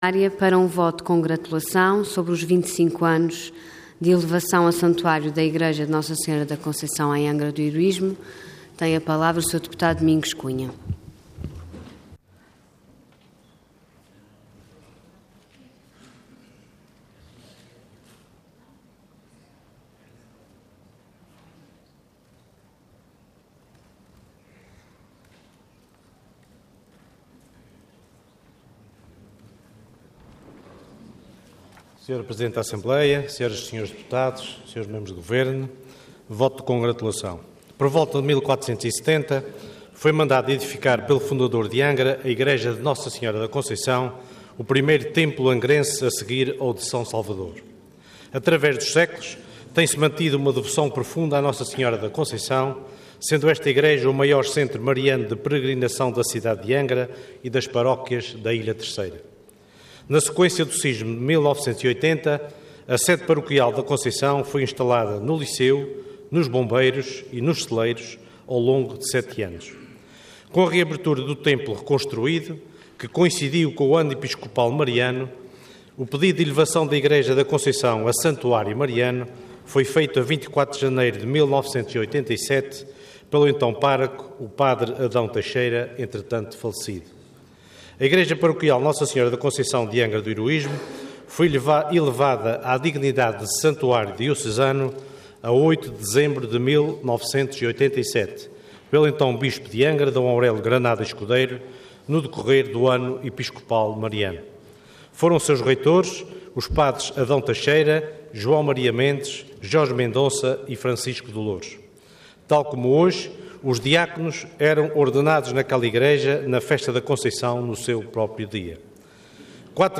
Intervenção Voto de Congratulação Orador Domingos Cunha Cargo Deputado Entidade PSD